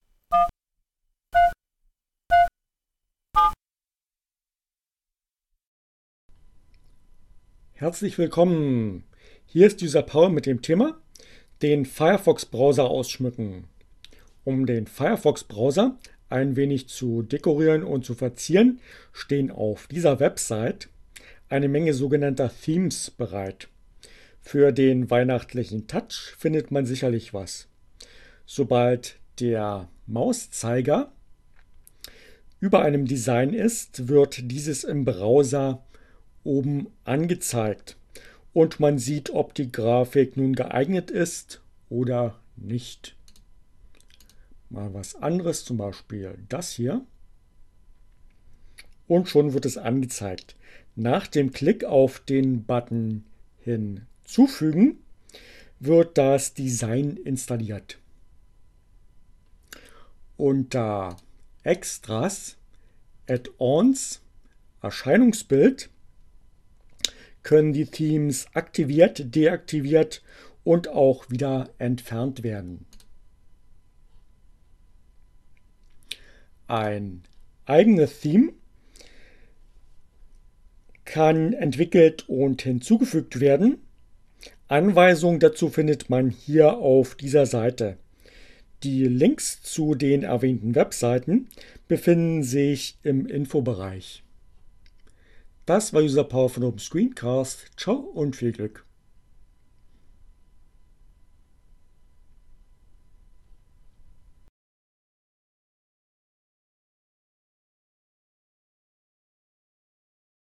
Tags: CC by, Linux, Neueinsteiger, ohne Musik, screencast, Firefox